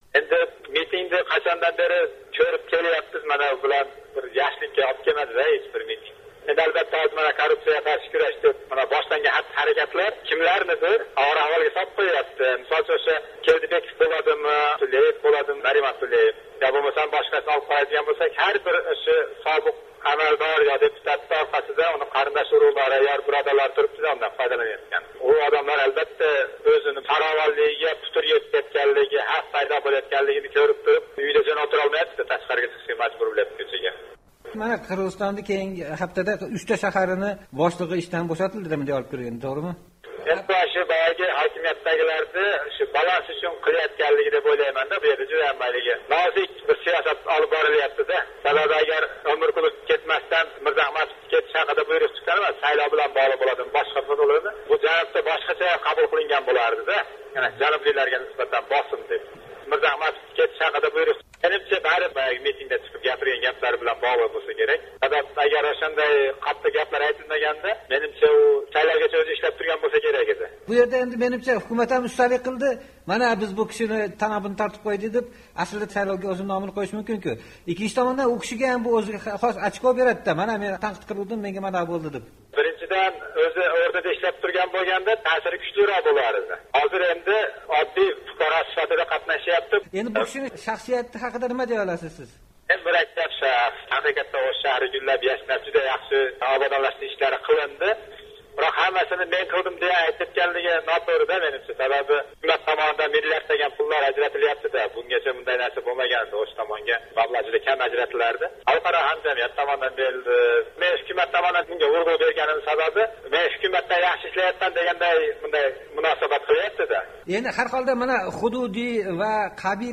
"Amerika ovozi" Qirg’izistondagi vaziyat xususida mahalliy aholi va tahlilchilar fikrini o’rgandi: